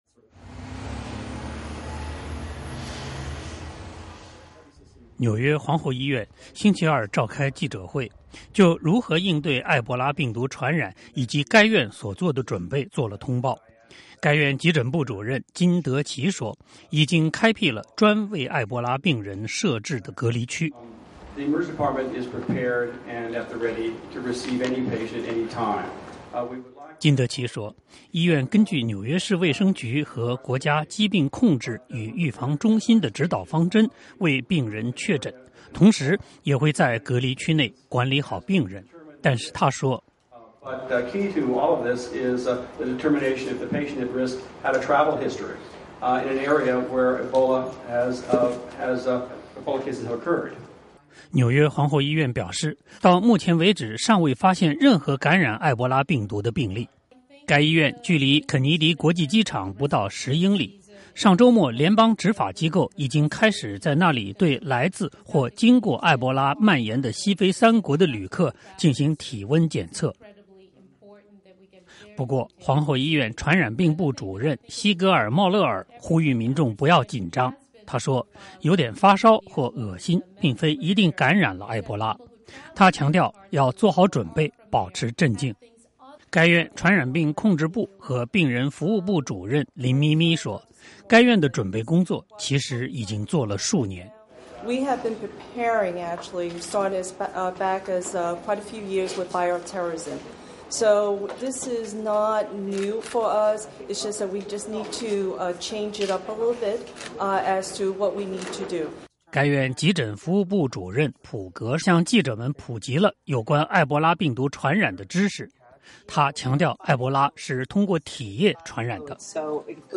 纽约一家靠近肯尼迪国际机场的医院星期二举行记者会，该院的传染病专家说，他们已经为应对埃博拉病人的到来做好了准备，不过到目前为止纽约还未见任何埃博拉病例。